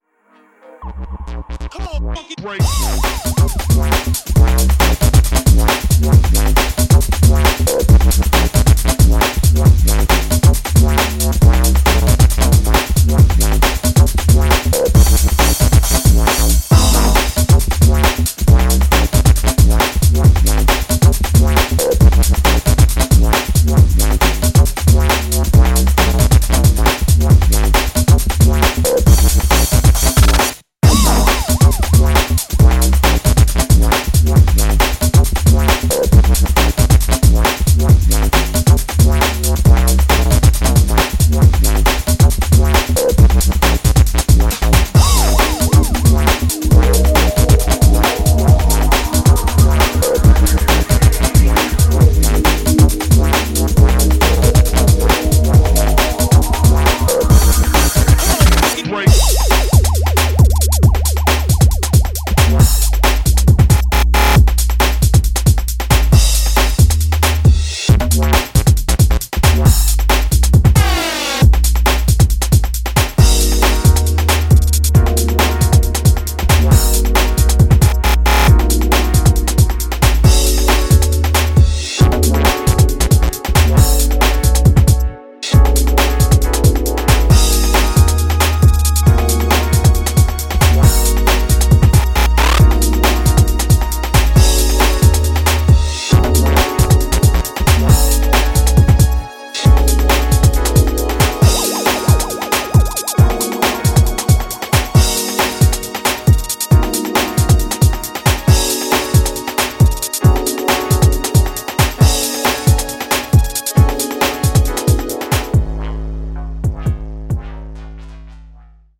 5 tracks of booming UKG and breaks.